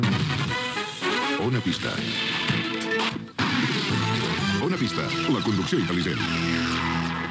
Indicatiu del programa i eslògan
FM